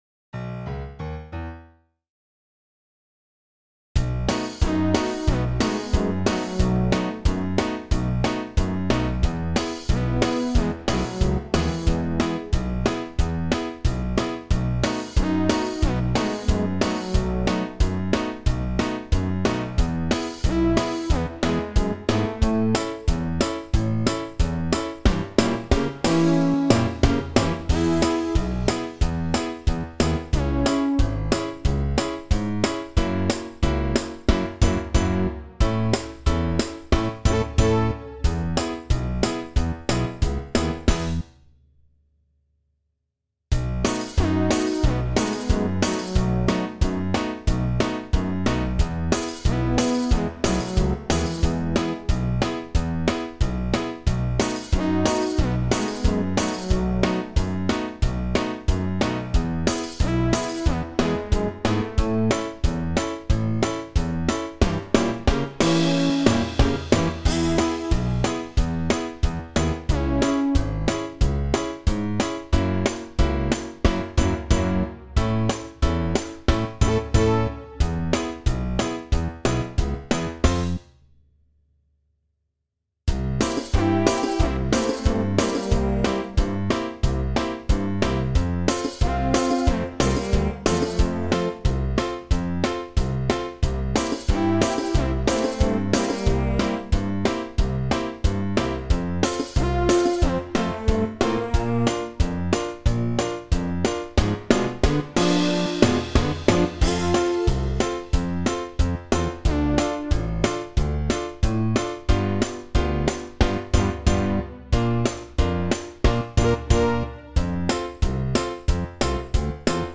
karaoke változata